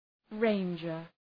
{‘reındʒər}